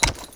trot2.wav